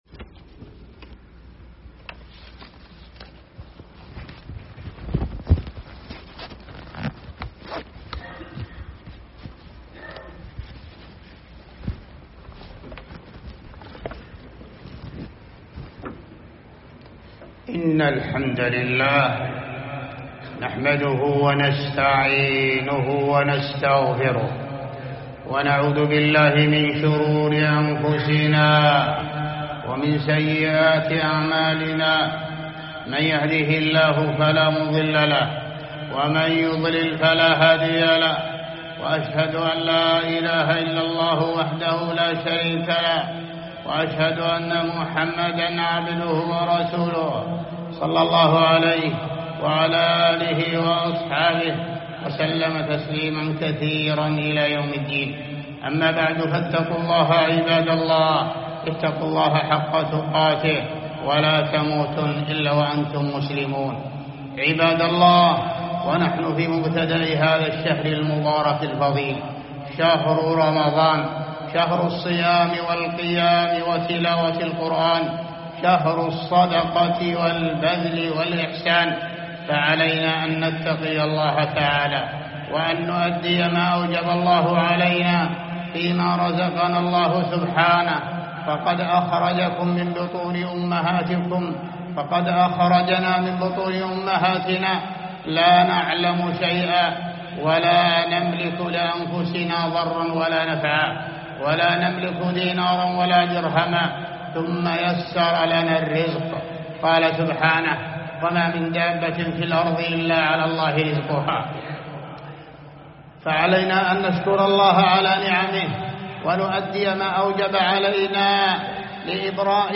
جامع الحارة الجنوبية بالنجامية منطقة جازان
مواعظ ورقائق